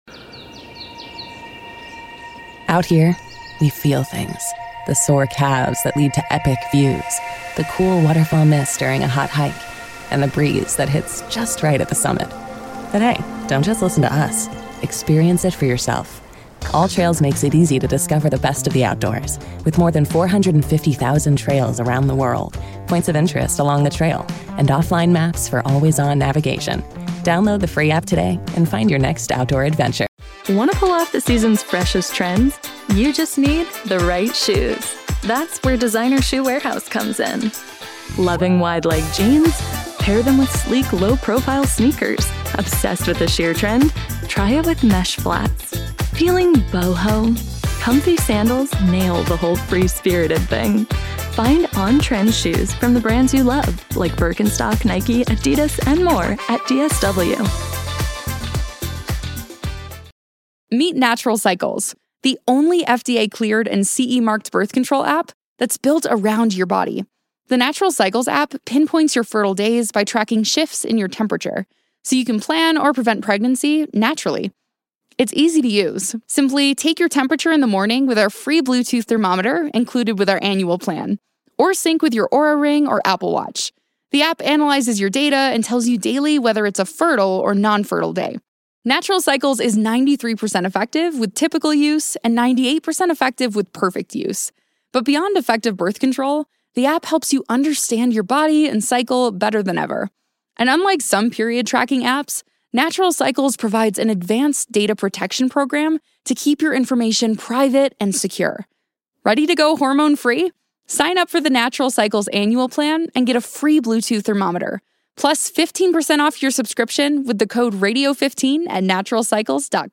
Eagles legendary defensive end Brandon Graham joins the WIP Midday Show live!